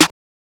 MURDA_SNARE_SIDE.wav